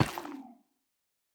Minecraft Version Minecraft Version 1.21.5 Latest Release | Latest Snapshot 1.21.5 / assets / minecraft / sounds / block / sculk_catalyst / step3.ogg Compare With Compare With Latest Release | Latest Snapshot
step3.ogg